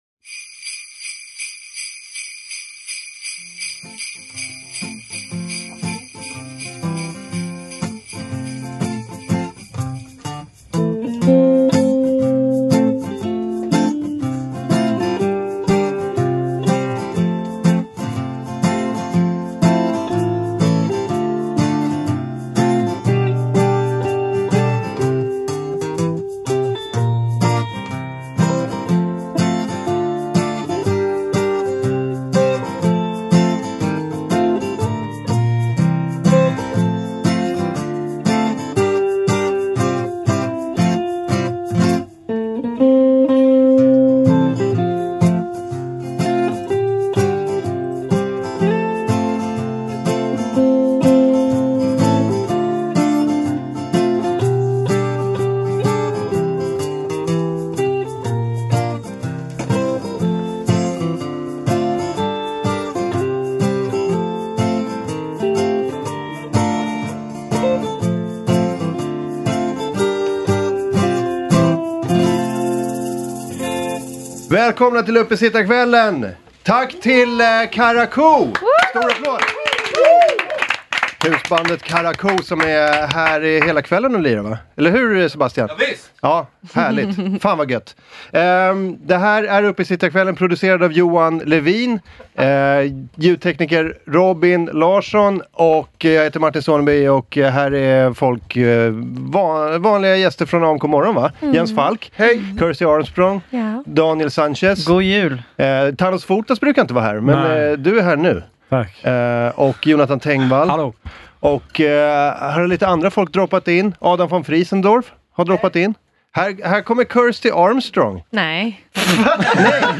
Medverkande: En hel del komiker
Downloads Download AMK_Uppesittarkvall_2018_Del_1.mp3 Content Här är AMK Morgons uppesittarkväll som spelades in i AMK Studios fredagen 14 december 2018.